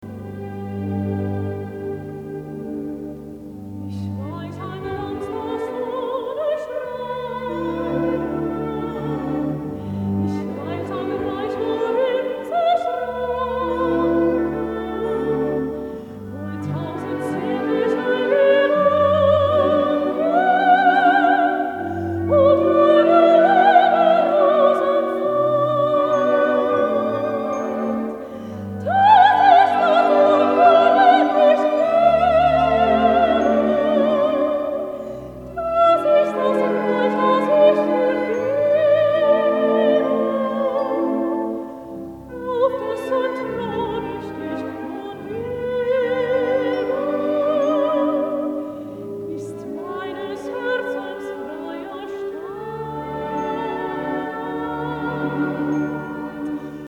Operette